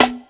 07_Perc_07_SP.wav